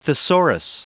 Prononciation du mot thesaurus en anglais (fichier audio)